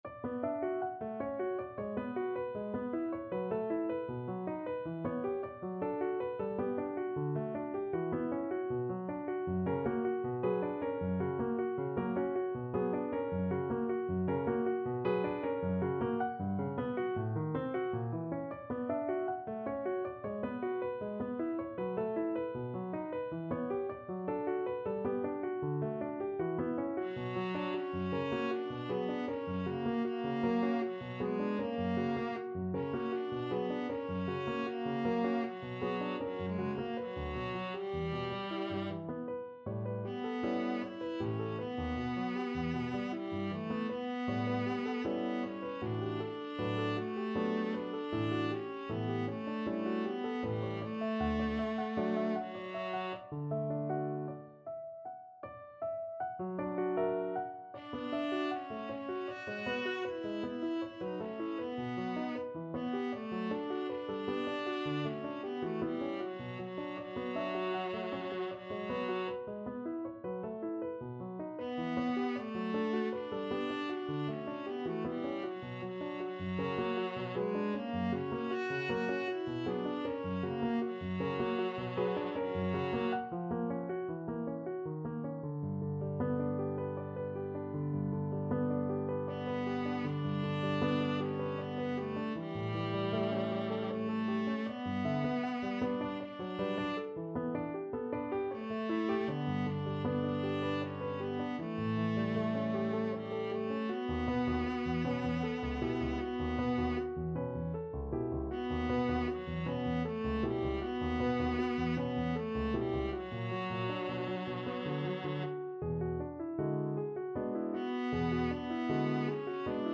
Viola version
3/4 (View more 3/4 Music)
Classical (View more Classical Viola Music)